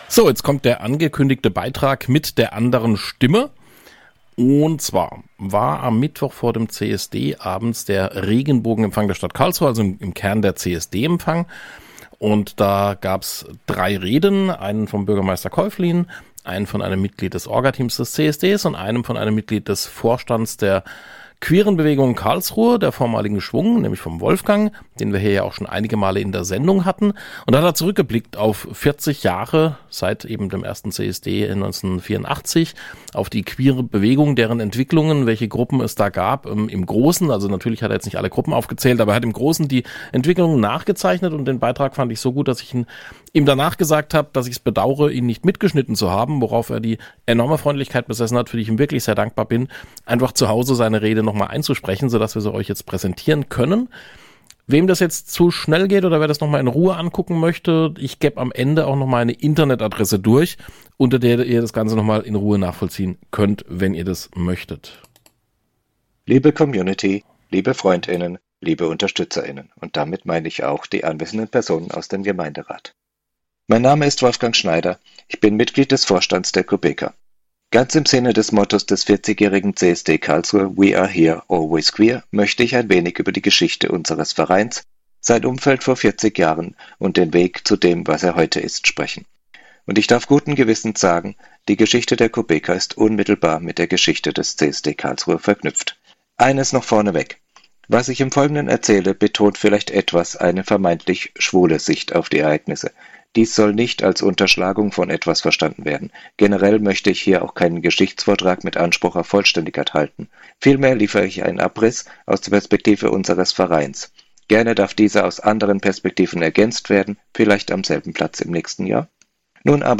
In unserem Redebeitrag zum Regenbogenempfang im Rathaus am 29. Mai 2024 könnt ihr lesen, wie unser Verein entstand und einiges mehr aus der damaligen Zeit, unserer Verbindung zum CSD Karlsruhe und was im Laufe der Jahre passiert ist. Wenn euch das zu viel zum Lesen ist, hört euch gerne die Radioversion aus der Rosarauschen-Sendung vom 07.06.2024 davon an.
Redebeitrag Regenbogenempfang 2024 - Radioversion.mp3